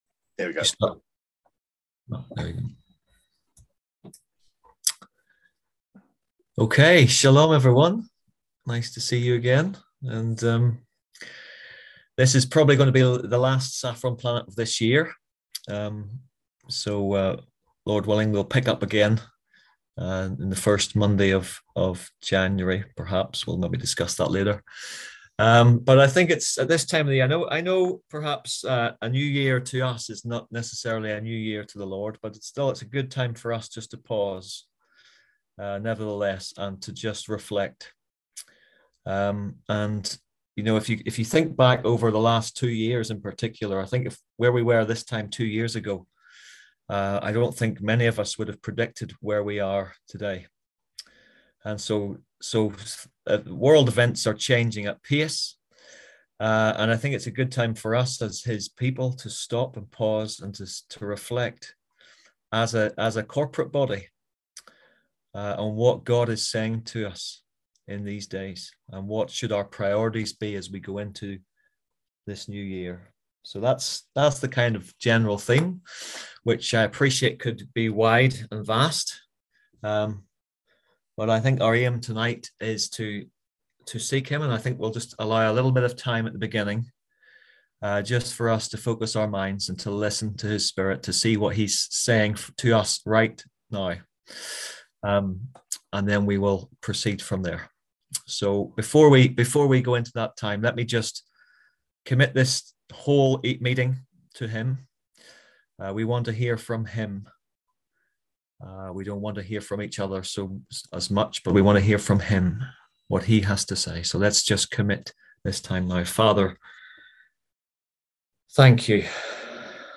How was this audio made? Click here to listen to the full story of our F14 conference, ‘when Christians disagree’.